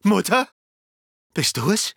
• Story! Eine reichhaltige Geschichte, erzählt als voll synchronisierten Tagebucheinträge und Selbstgespräche des Protagonisten
Die zu hörende Stimme ist keine unbekannte: Der deutsche Synchronsprecher Dennis Schmidt-Foß (Deadpool) spricht die Hauptfigur von Dead Man’s Diary. Etwa 23.700 Wörter hat Dennis Schmidt-Foß für den narrativen Survival-Titel im Studio aufgenommen.